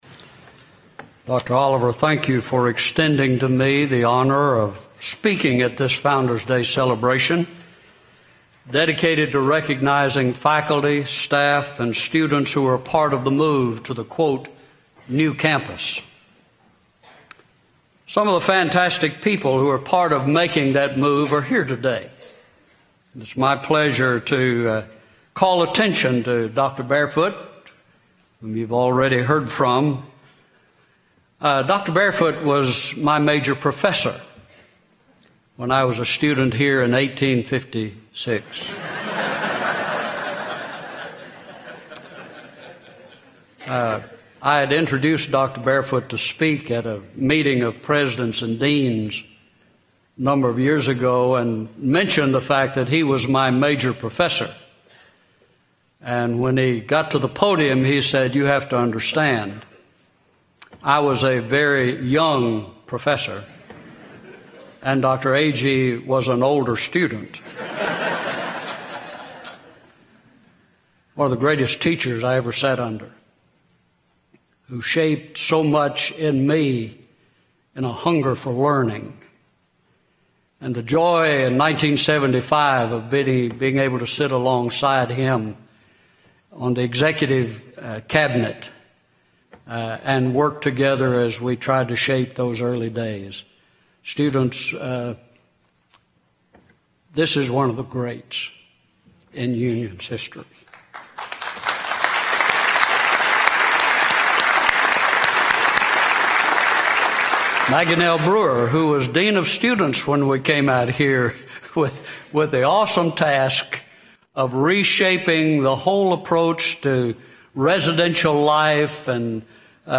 Founders' Day Chapel